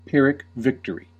Ääntäminen
Ääntäminen US : IPA : [ˌpɪ.ɹɪk ˈvɪk.tɚ.i]